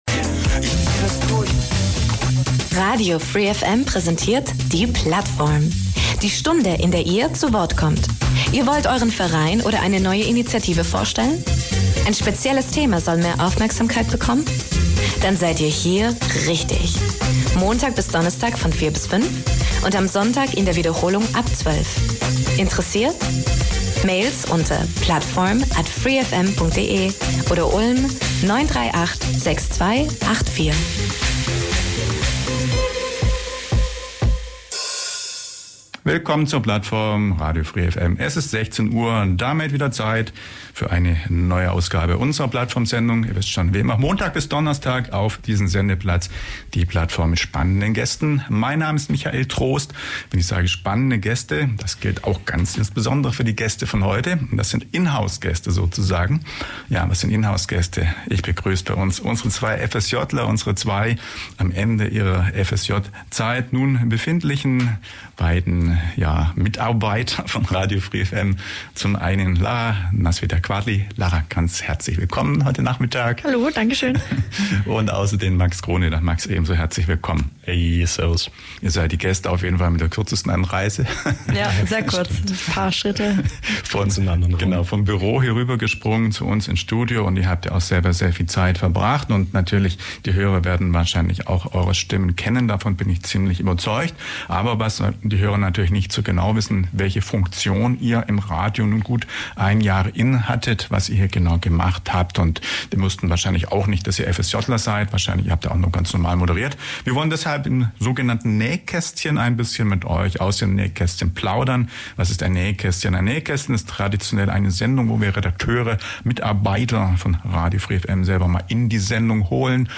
Für die heutige Plattform können wir in unserem Büro bleiben, denn zu Gast sind dieses Mal die beiden FSJler von Radio free FM.